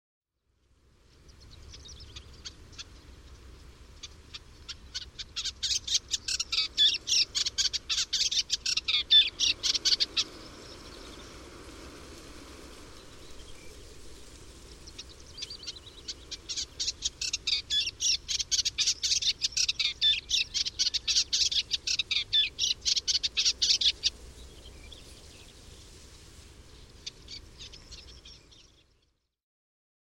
hypolais-pale.mp3